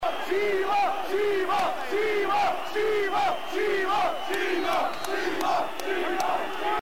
FCM-Fansongs und Blocklieder